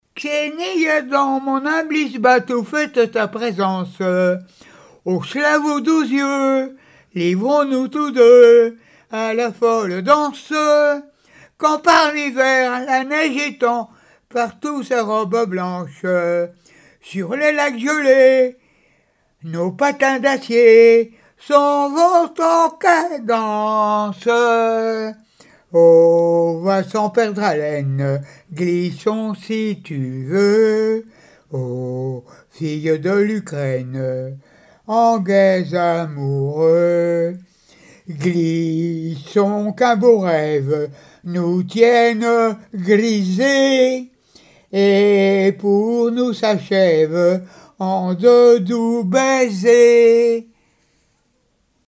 Mémoires et Patrimoines vivants - RaddO est une base de données d'archives iconographiques et sonores.
Genre strophique
chansons traditionnelles et d'école
Pièce musicale inédite